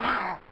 scrump_shot.ogg